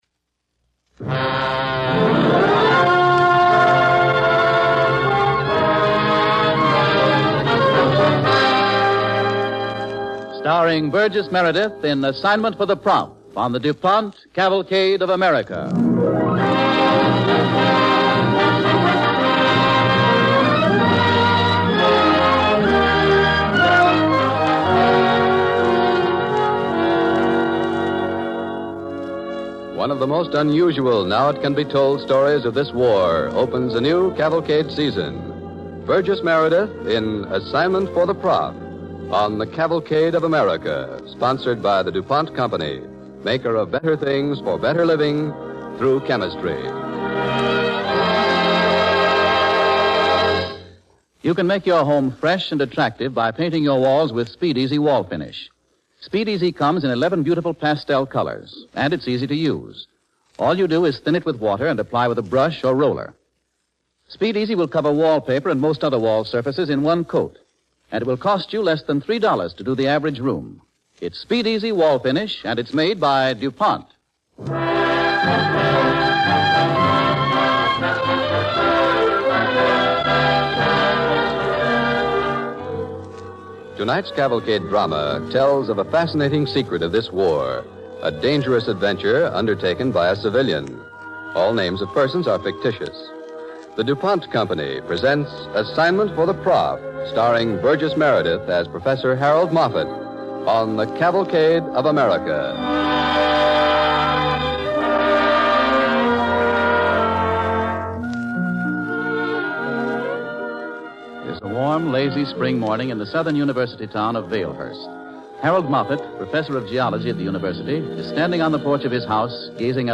Cavalcade of America Radio Program
starring Burgess Meredith and Theodore Von Eltz